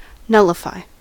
nullify: Wikimedia Commons US English Pronunciations
En-us-nullify.WAV